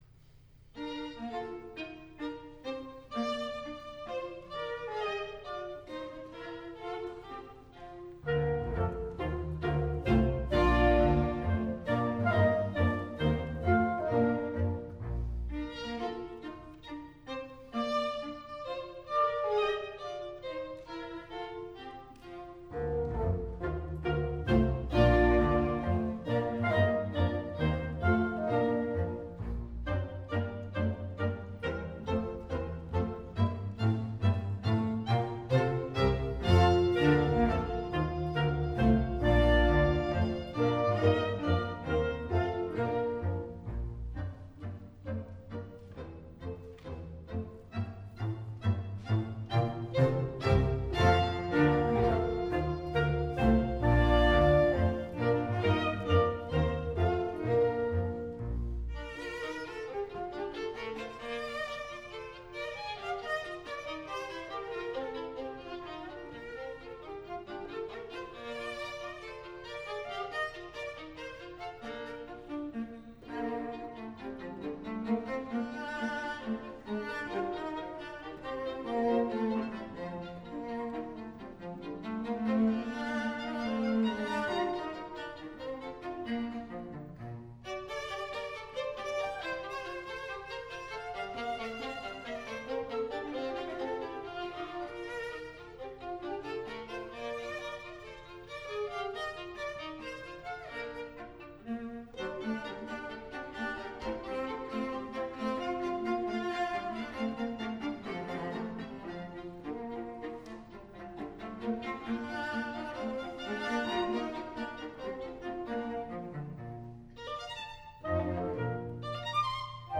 Historic Chamber Music Recordings - Marlboro Music Festival
Listen to historic chamber music recordings online as heard at Vermont's Marlboro Music Festival, classical music's most coveted retreat since 1951.